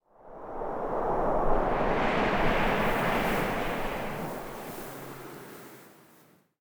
housewind16.ogg